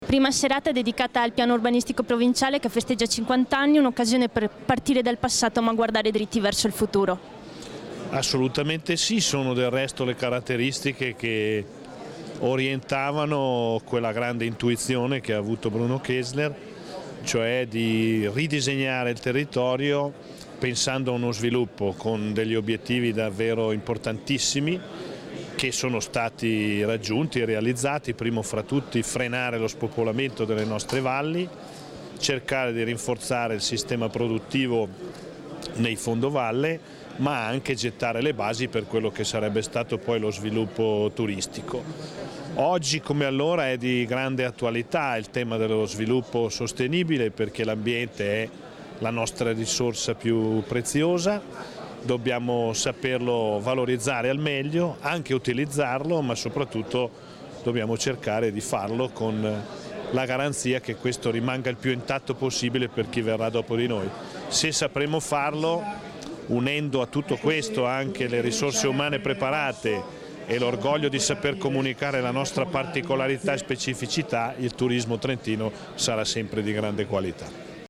Intervista presidente Rossi